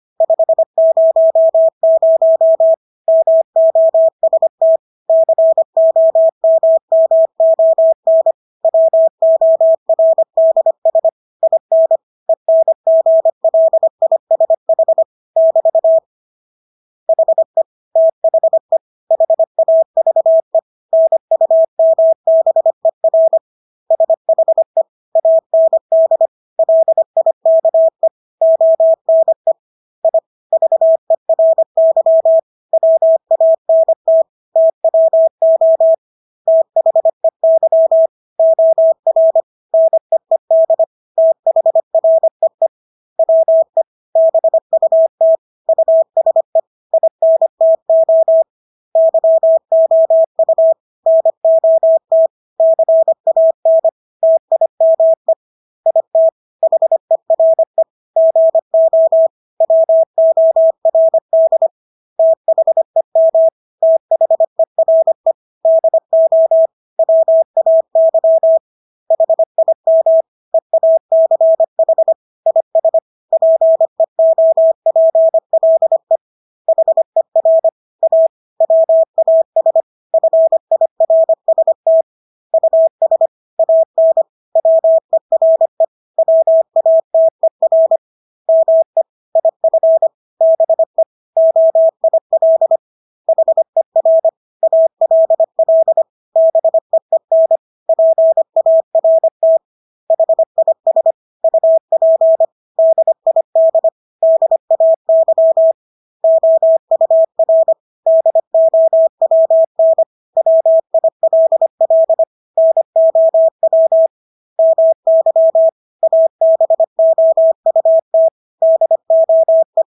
Engelske ord 25 wpm | CW med Gnister
Engelske ord 25-25 wpm.mp3